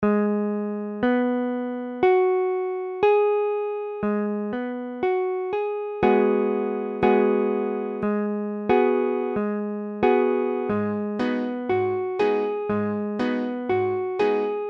Tablature Abm7.abcAbm7m7 : accord de La bémol mineur septième
Mesure : 4/4
Tempo : 1/4=60
La bémol mineur septième barré IV (la bémol case 4 doigt 1 mi bémol case 6 doigt 2 la bémol case 6 doigt 3 do bémol case4 doigt 1 sol bémol case 7 doigt 4 la bémol case 4 doigt 1)
Abm7.mp3